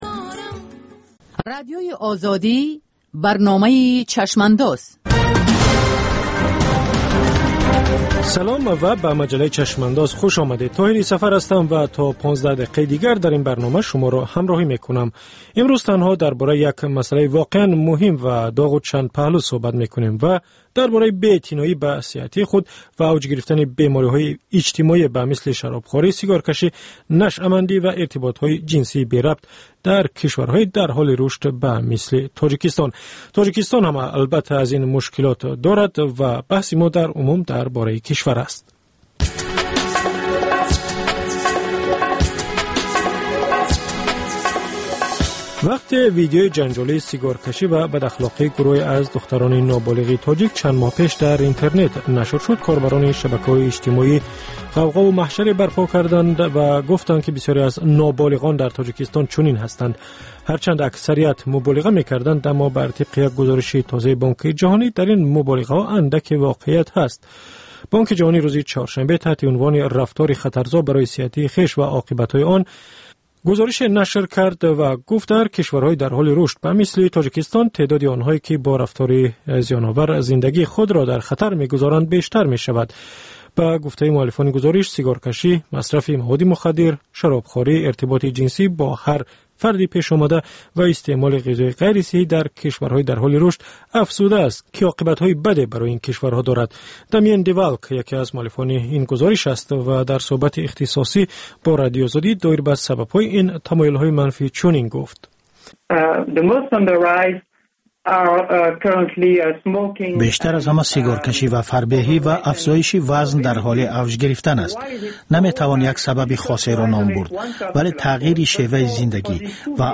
Баррасии рӯйдодҳои сиёсии Тоҷикистон, минтақа ва ҷаҳон дар гуфтугӯ бо таҳлилгарон.